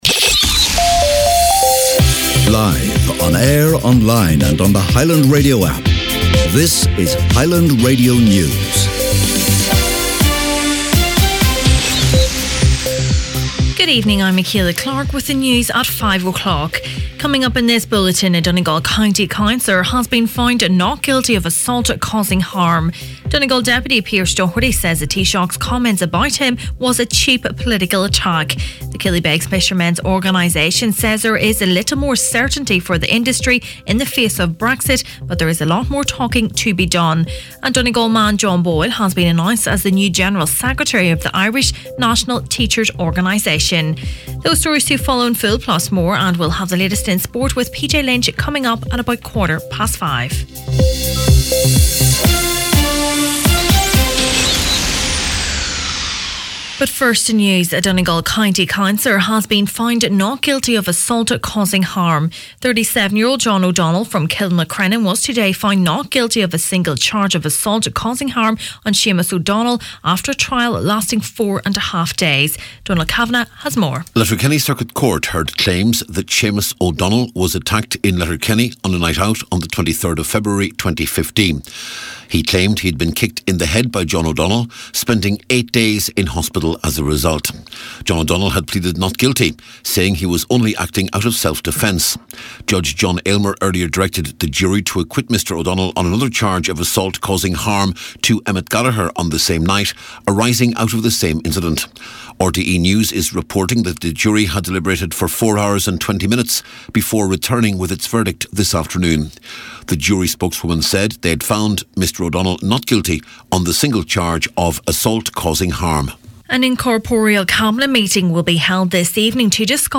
Main Evening News, Sport and Obituaries Wednesday December 19th